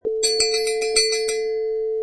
Sound bytes: Cowbell 3
High quality recording of several cowbell hits
Product Info: 48k 24bit Stereo
Category: Percussion / Bells
Try preview above (pink tone added for copyright).
Cowbell_3.mp3